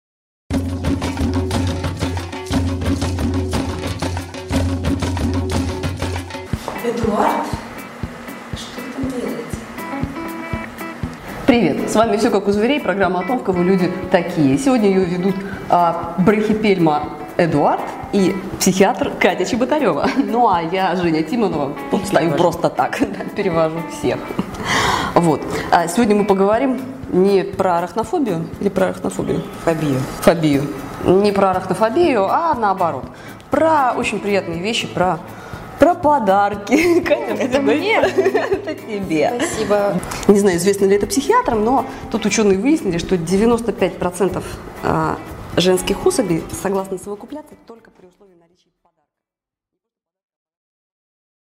Аудиокнига Пауки: война полов. Мужское коварство против женской корысти | Библиотека аудиокниг